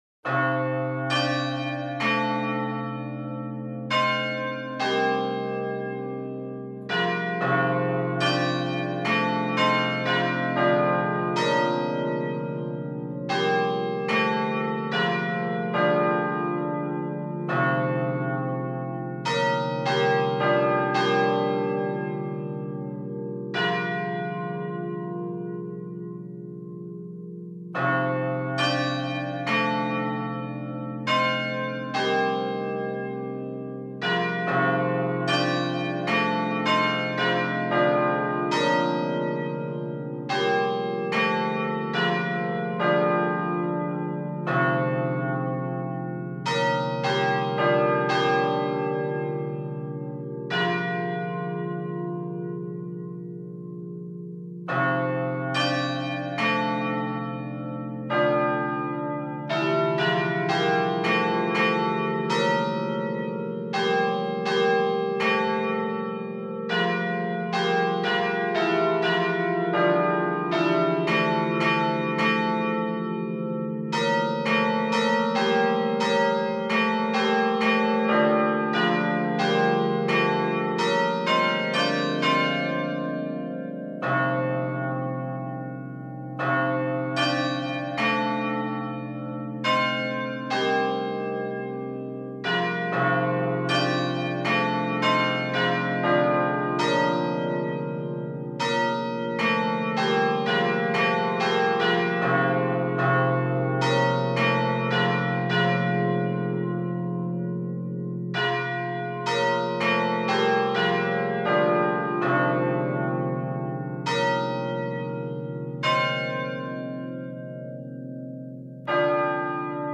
chimes was written for the re-dedication